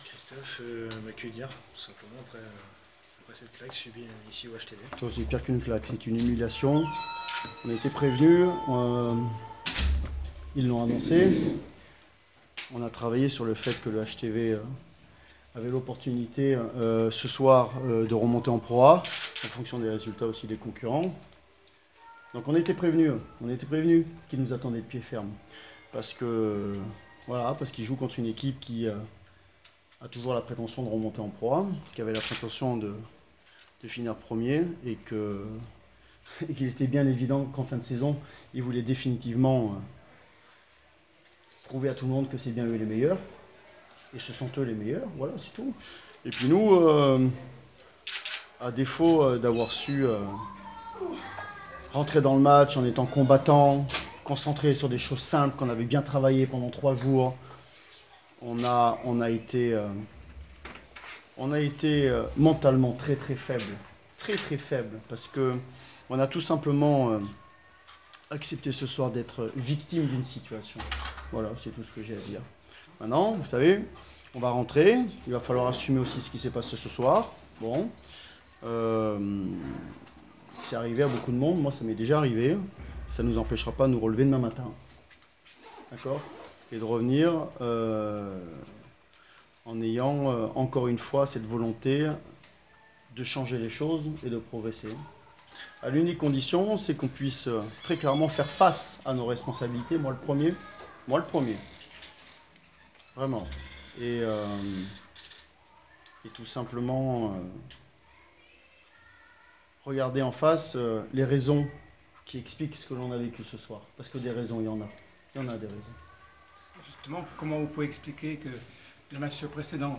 Interviews d'après match - JL Bourg Basket